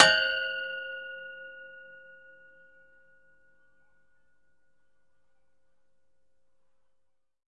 命中 金属 " 命中 金属 篮球圈管01
描述：击中撑起篮筐的杆子，用木杆。 用Tascam DR40录制。
Tag: 金属 篮下球 轰的一声 撞击 木材 打击乐 金属 体育 注意 影响 体育 沥青 baskbetball 碰撞 振铃音